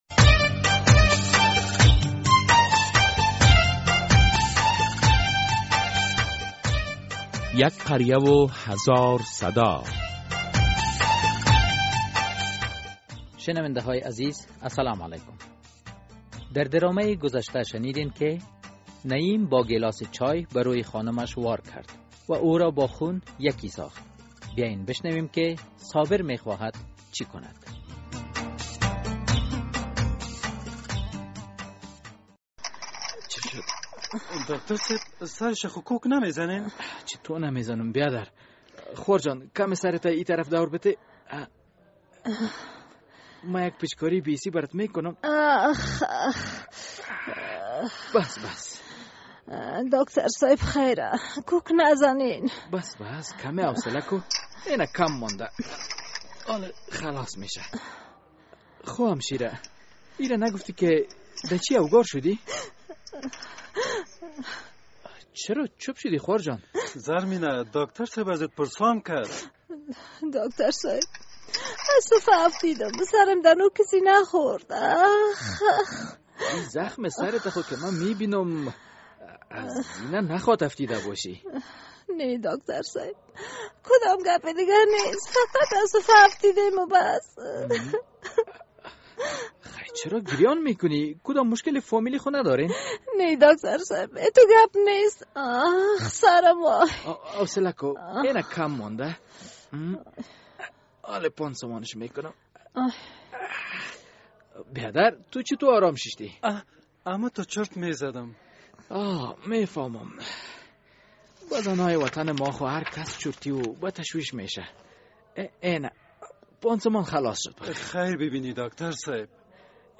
در این درامه که موضوعات مختلف مدنی، دینی، اخلاقی، اجتماعی و حقوقی بیان می‌گردد هر هفته به روز های دوشنبه ساعت ۰۳:۳۰ عصر از رادیو آزادی نشر می‌گردد.